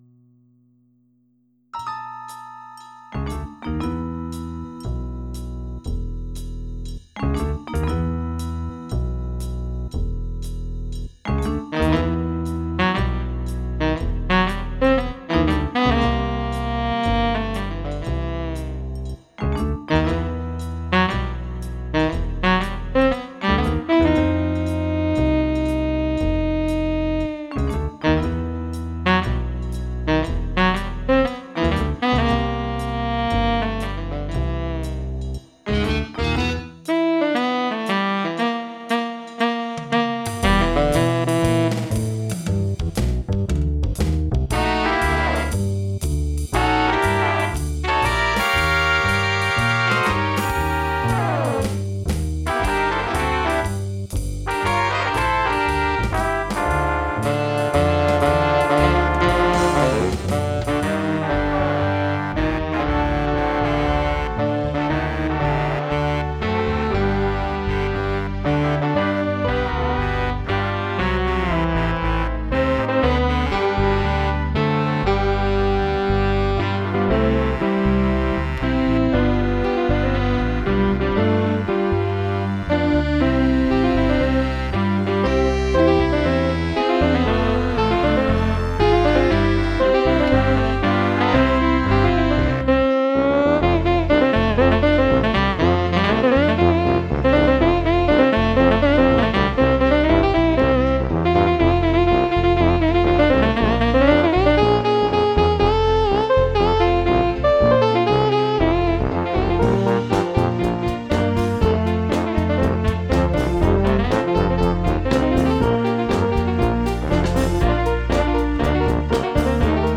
Theme: Tenor Saxophone (2 min)